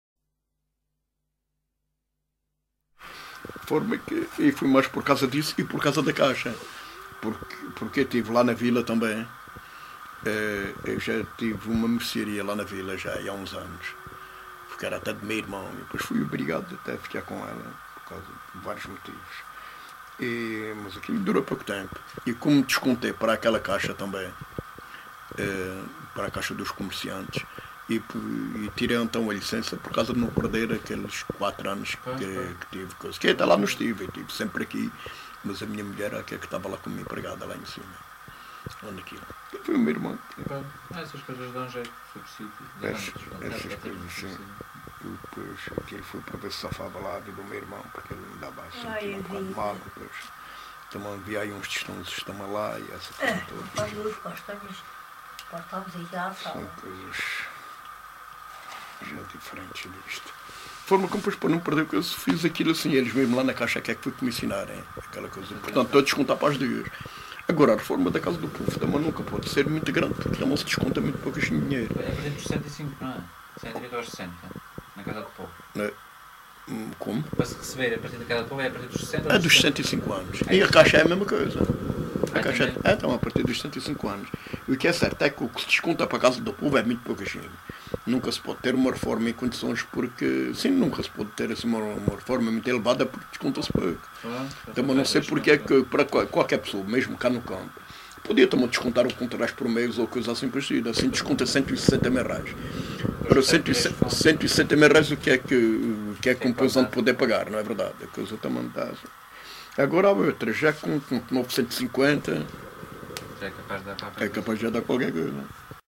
LocalidadeSapeira (Castelo de Vide, Portalegre)